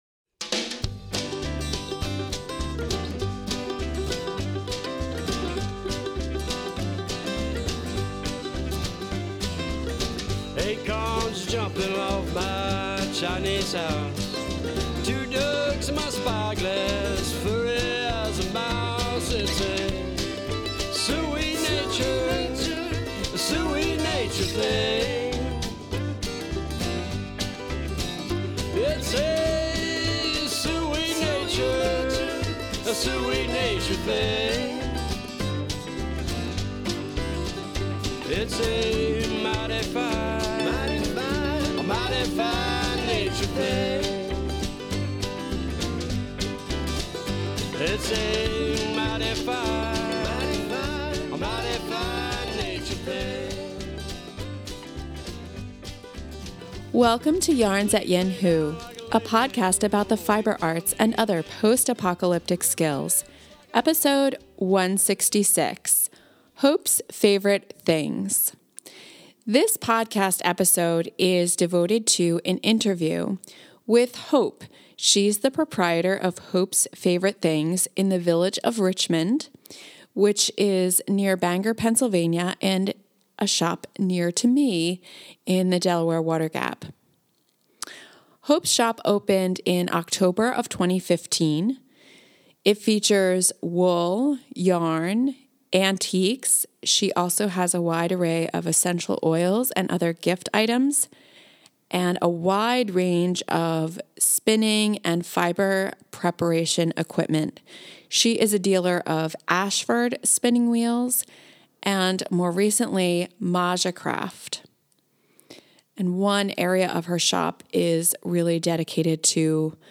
This episode features an interview